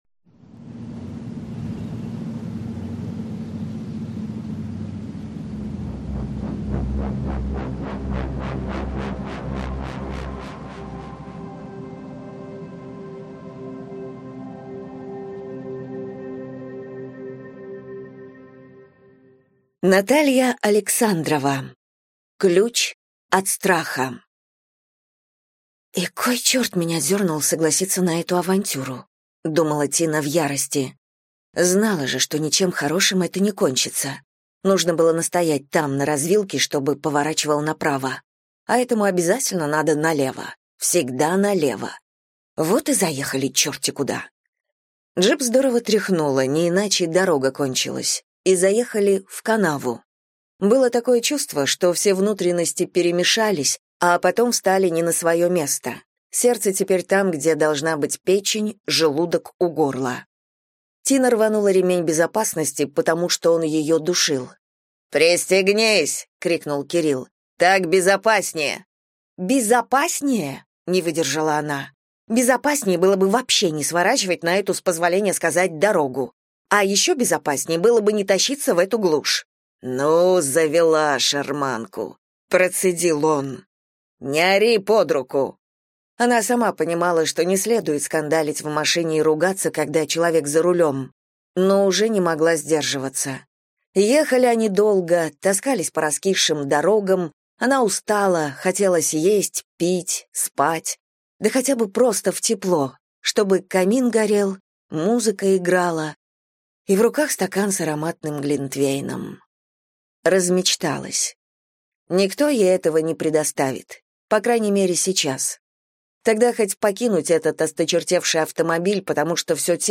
Аудиокнига Ключ от страха | Библиотека аудиокниг